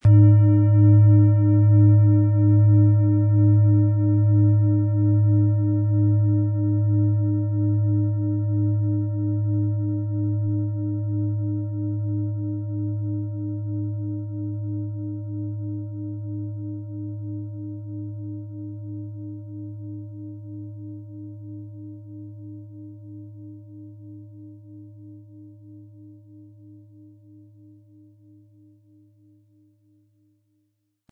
Planetenton 1
Es ist eine von Hand gearbeitete tibetanische Planetenschale Neptun.
MaterialBronze